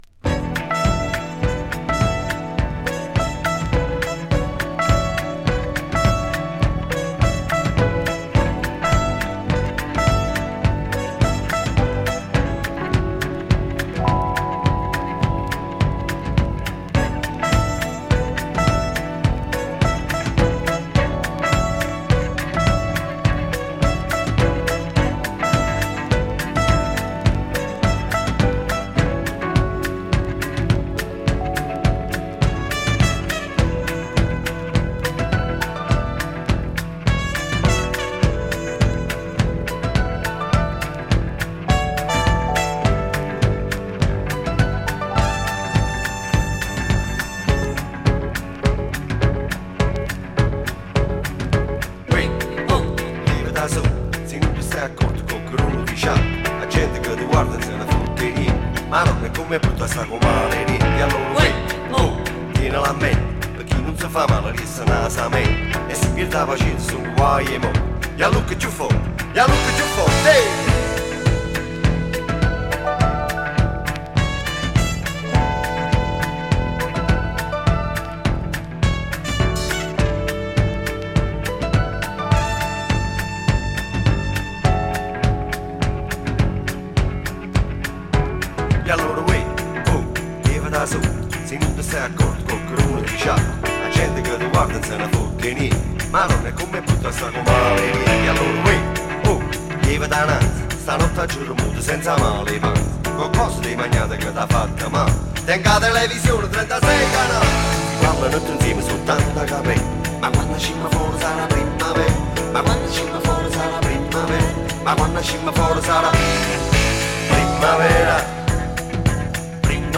BALEALIC CLASSIC!
A familiar Italo disco number as a classic Balearic number!